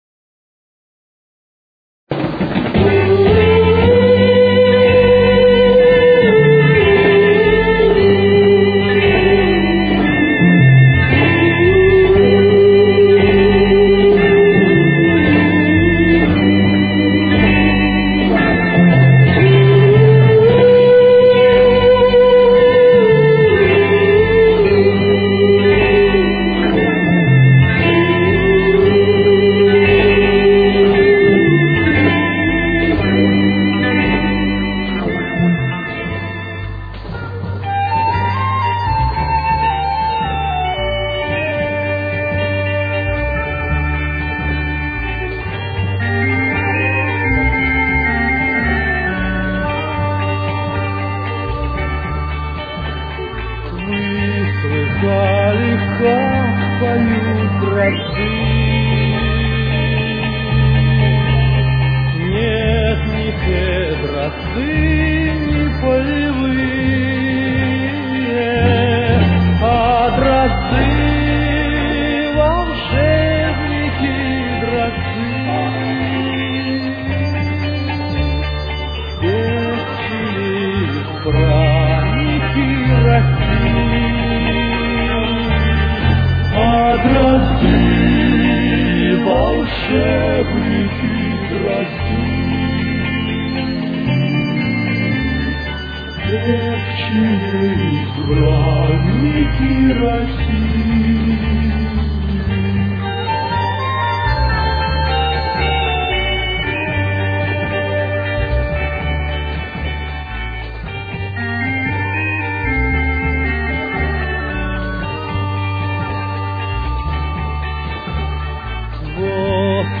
Тональность: Соль минор. Темп: 116.